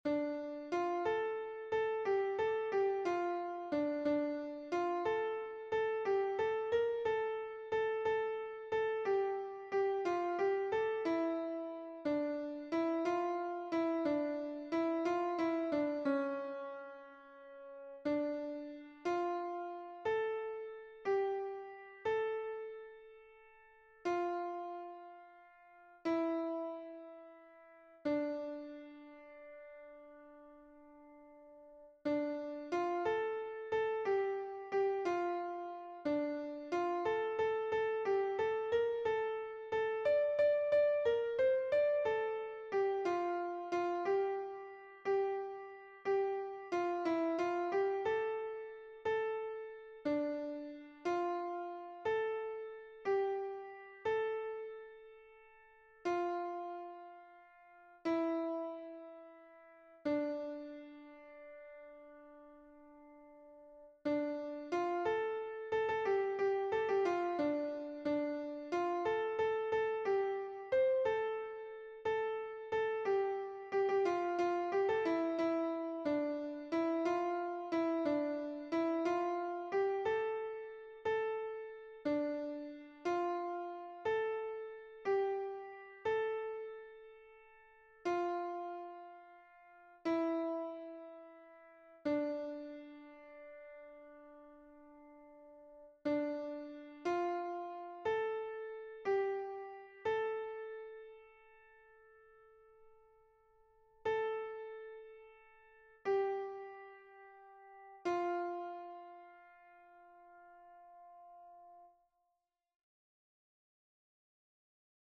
Mp3 version piano
Soprano